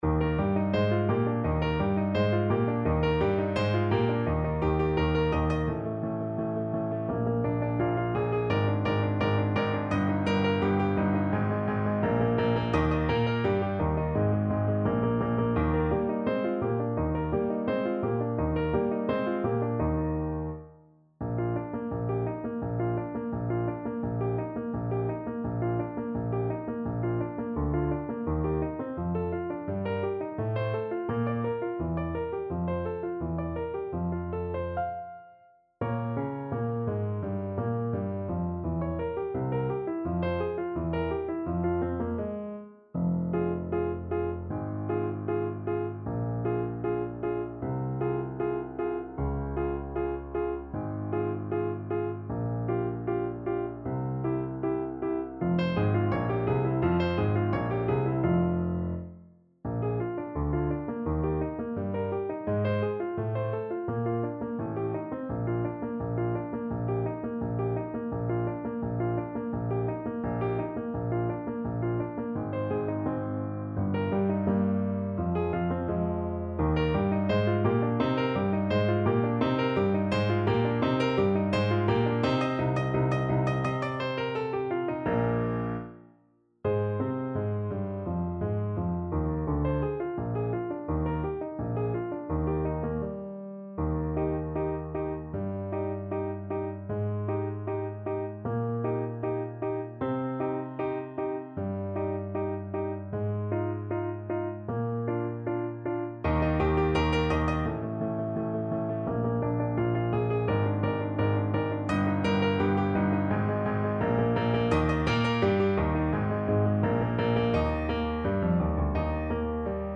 ~ = 170 Tempo giusto
Classical (View more Classical Bass Voice Music)